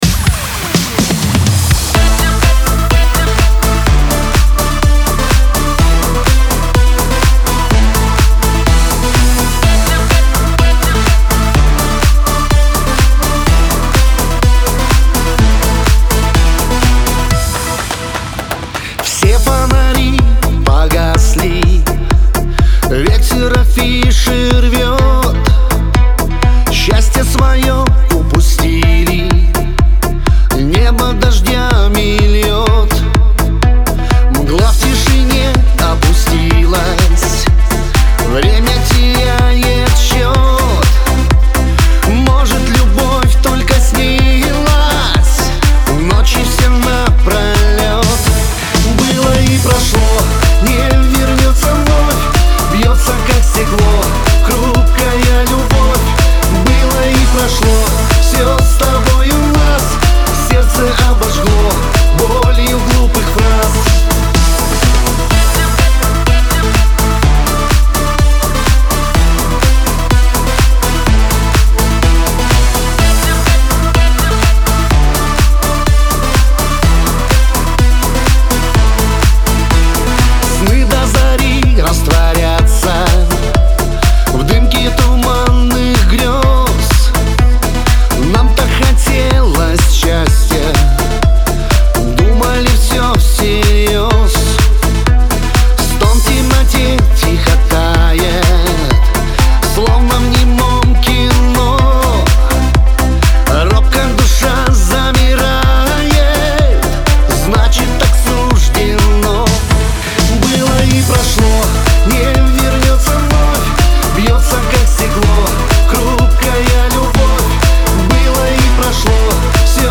pop
Лирика